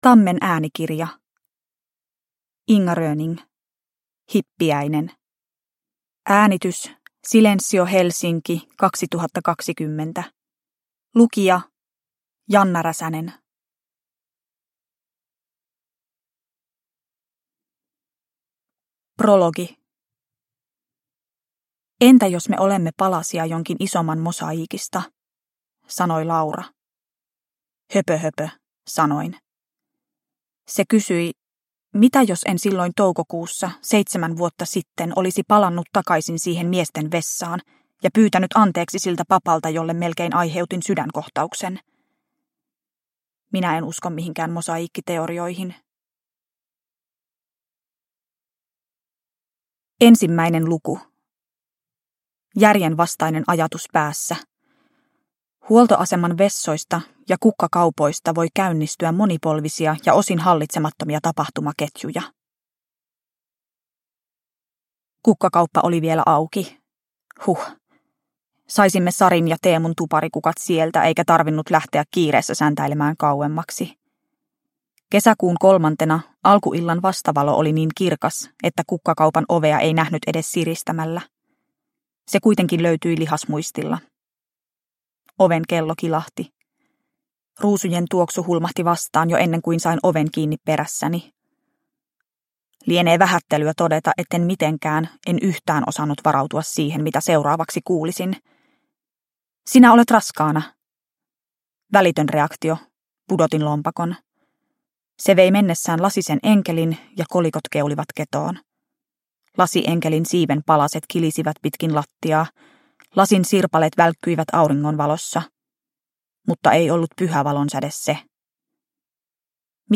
Hippiäinen – Ljudbok – Laddas ner